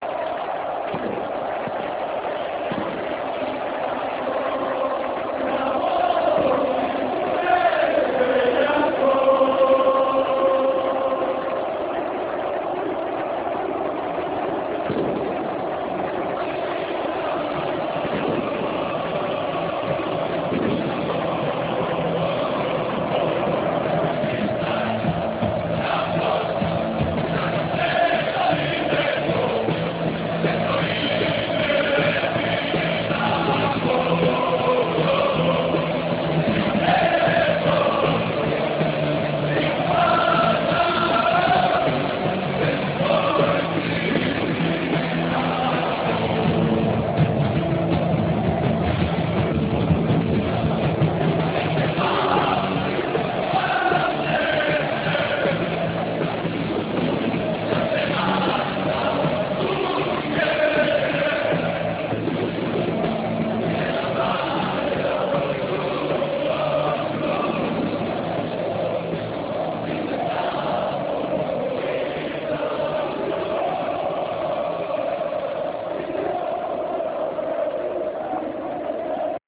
Snimke od kojih je nastao film napravljene su digitalnim fotoaparatom, za vrijeme utakmica. Kvaliteta tih snimaka je loša zbog specifičnih uvjeta, te je to dodatno umanjilo kvalitetu samog filma.